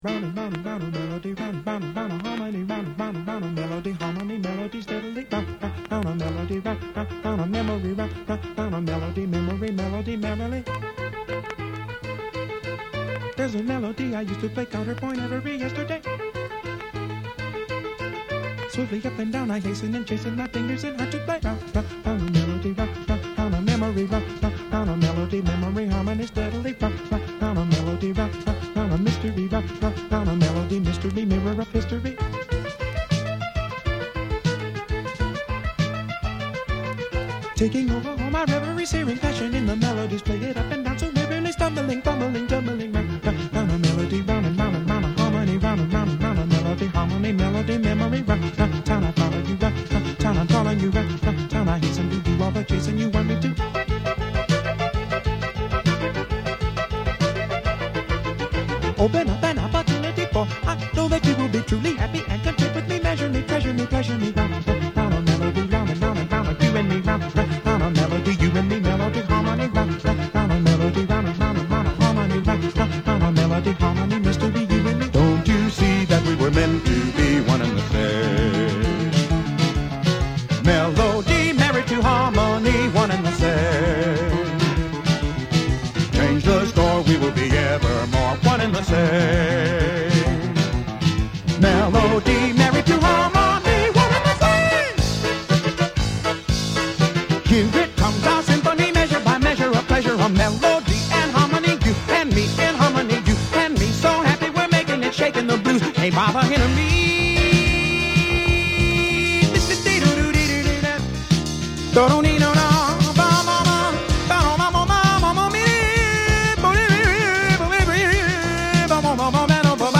e anche la Musica Classica